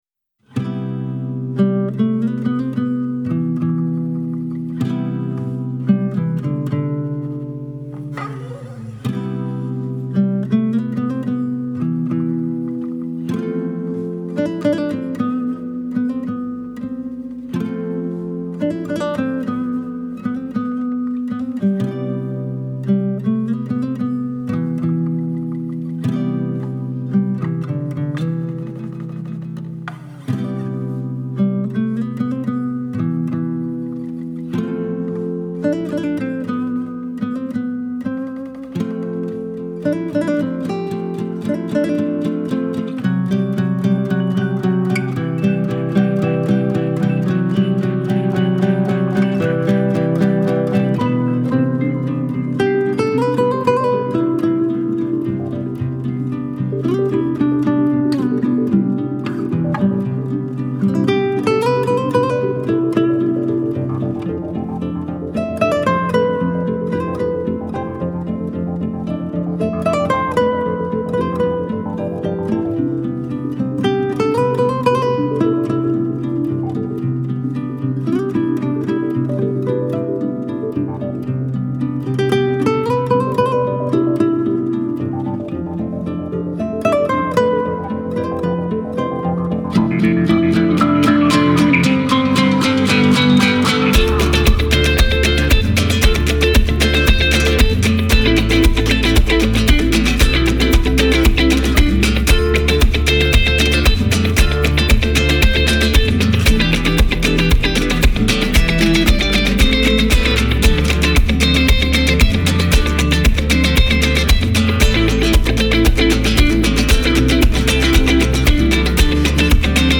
Genre : Latin